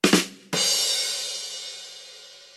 08 sfx Rim Shot